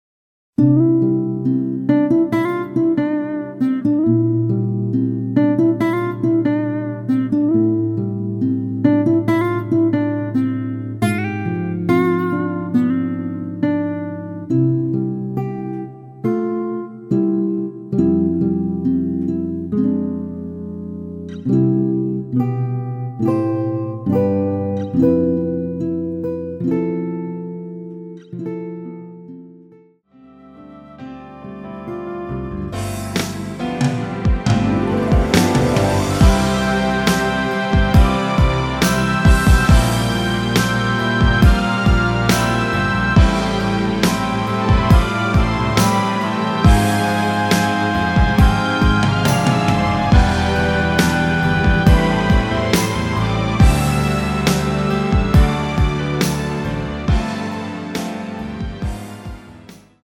음정은 반음정씩 변하게 되며 노래방도 마찬가지로 반음정씩 변하게 됩니다.
앞부분30초, 뒷부분30초씩 편집해서 올려 드리고 있습니다.
중간에 음이 끈어지고 다시 나오는 이유는
곡명 옆 (-1)은 반음 내림, (+1)은 반음 올림 입니다.